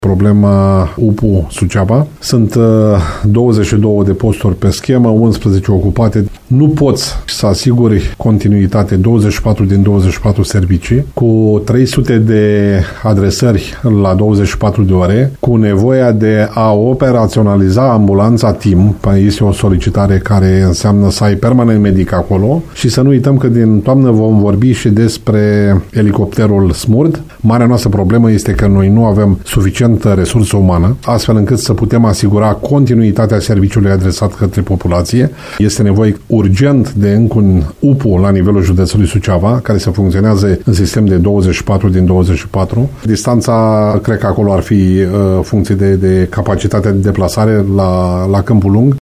El a declarat postului nostru că o asemenea UPU ar mai decongestiona activitatea și așa aglomerată de la cel mai important spital al județului.